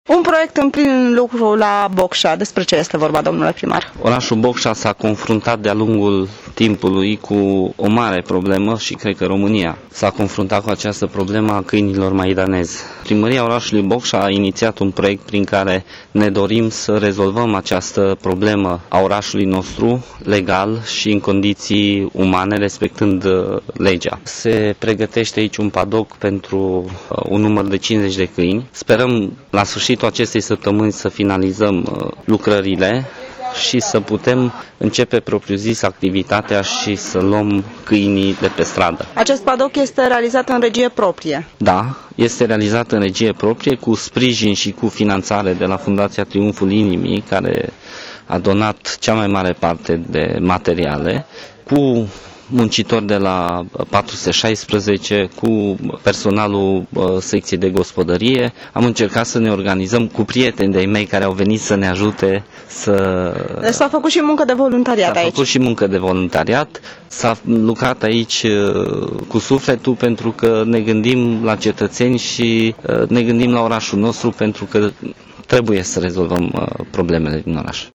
a stat de vorbă pe şantier cu primarul Eugen Cismăneanţu pentru a afla care este scopul noii construcţii: